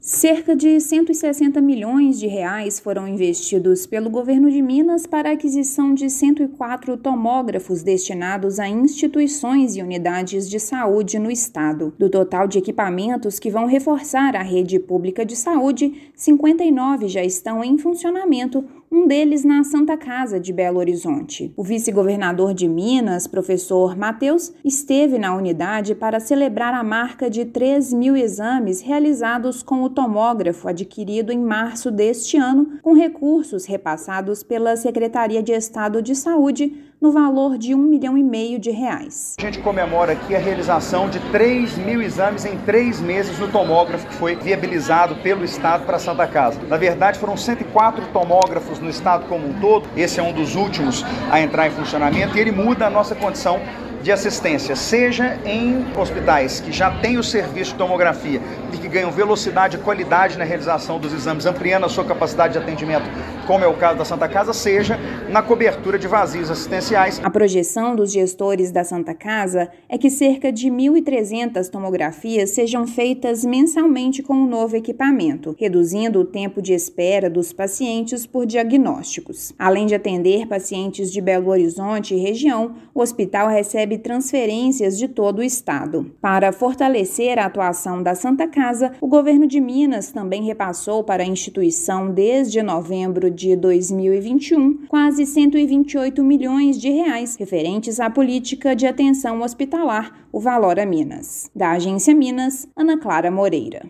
Com investimento de R$ 160 milhões para a compra de tomógrafos, expectativa é de redução do tempo de espera para a realização do exame pelo SUS. Ouça matéria de rádio.